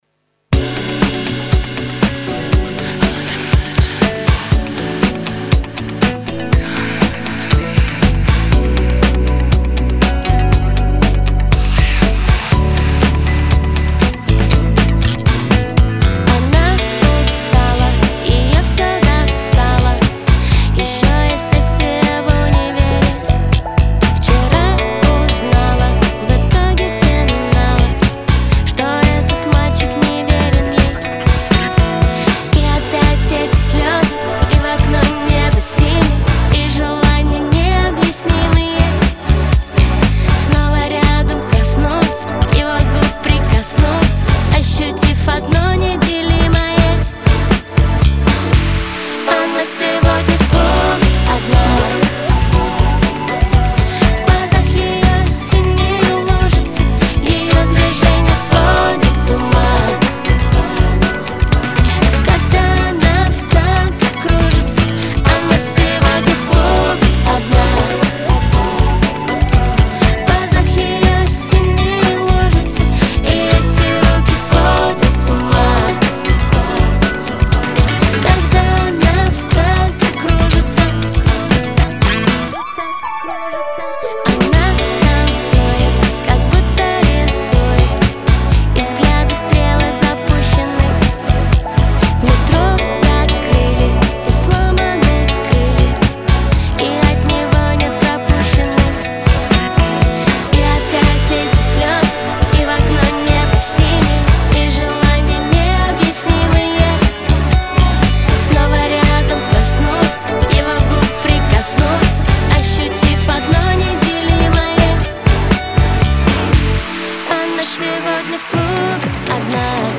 Было дано - вокал\бэки.